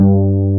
BAS.FRETG2-R.wav